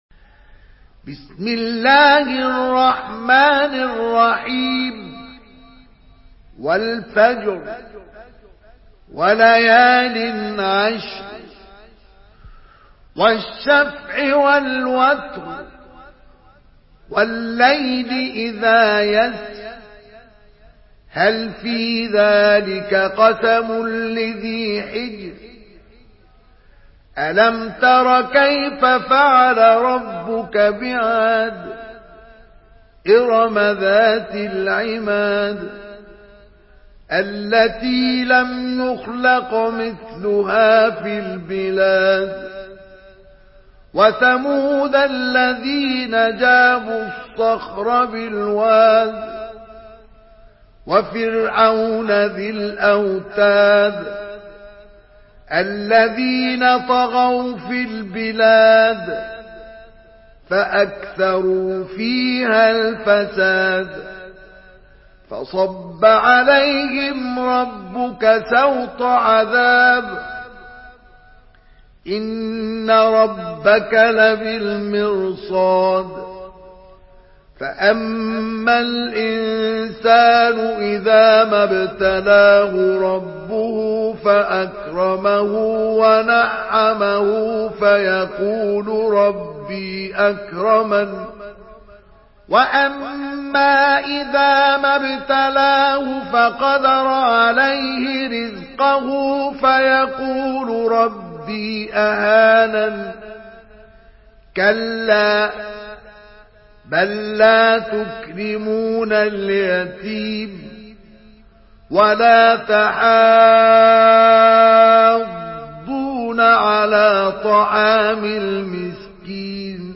Surah Fecr MP3 by Mustafa Ismail in Hafs An Asim narration.
Murattal Hafs An Asim